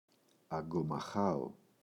αγκομαχάω [aŋgoma’xao] – ΔΠΗ